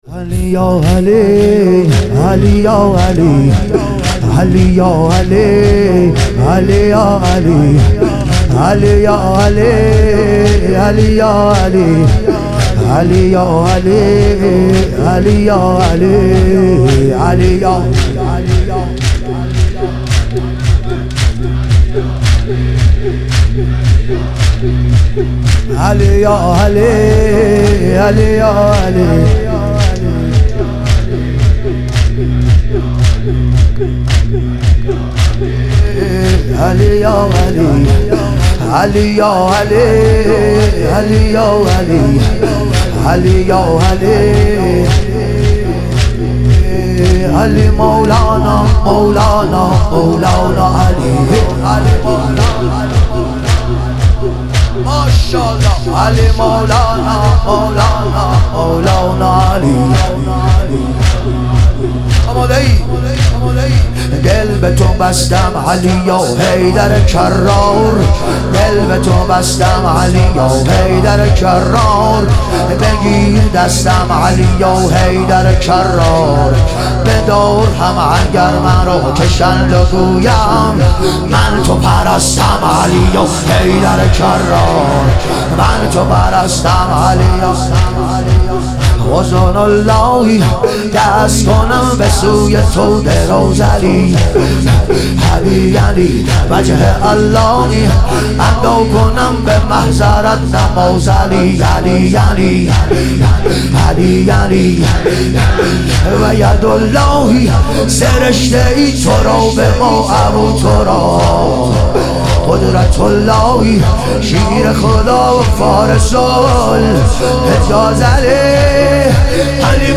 شب شهادت امام حسن مجتبی علیه السلام